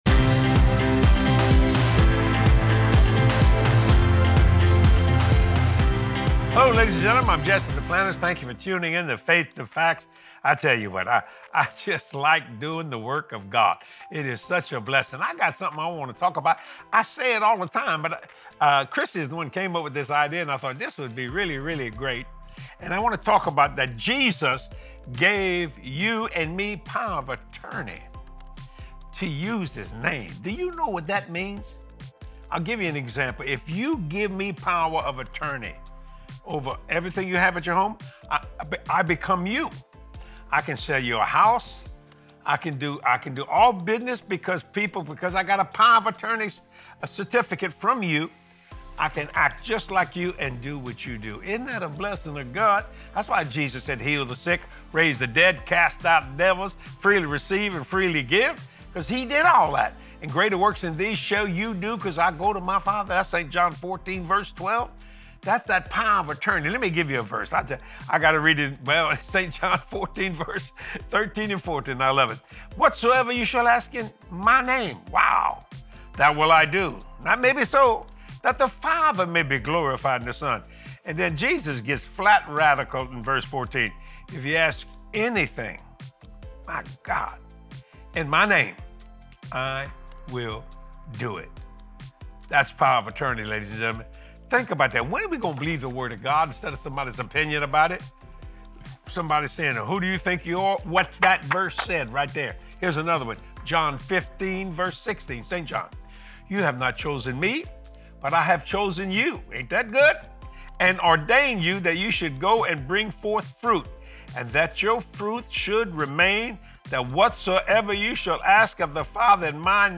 You have authority to USE the Name of Jesus! Watch this faith filled teaching from Jesse and start walking in the TRUTH of God’s Word on a daily basis